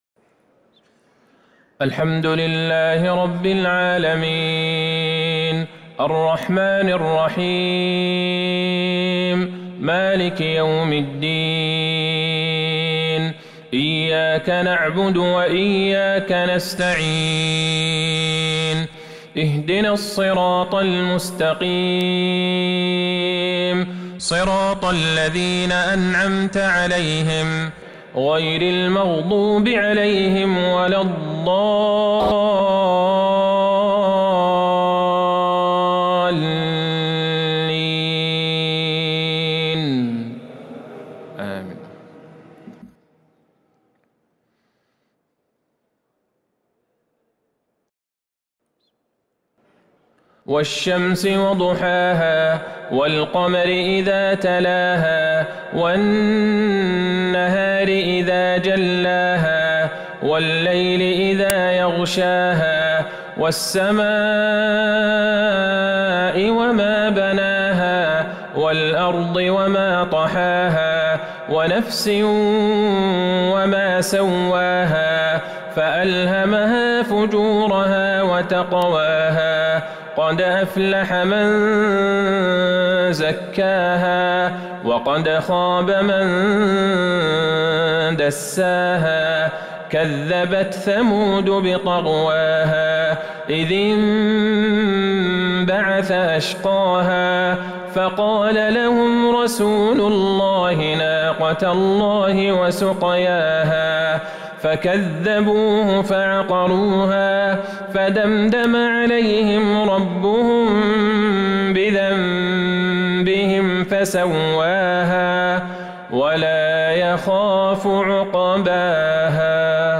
عشاء الجمعة ٢٦ محرم ١٤٤٣هـ | سورتي الشمس والضحى | Isha prayer from Surah Al-Shams & Al-Dhuha 3-9-2021 > 1443 🕌 > الفروض - تلاوات الحرمين